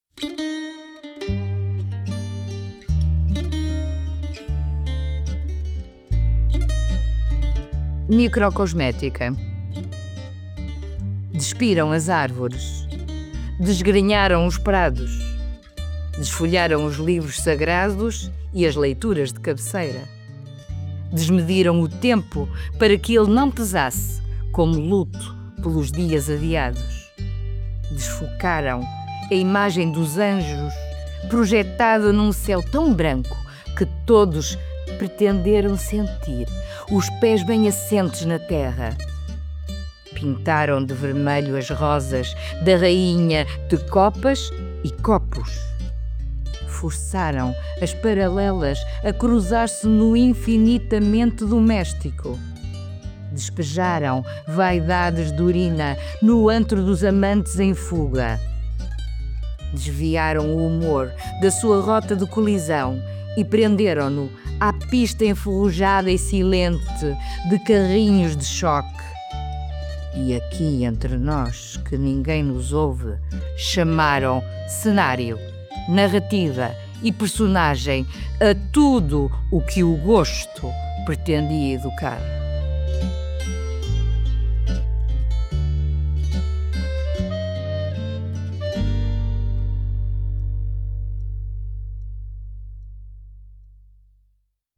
“Microcosmética”, de Regina Guimarães, in Levantamento, 29 de janeiro de 2024 – 29 de fevereiro de 2024, p. 41 Música: Fado Menor, licença Cantar Mais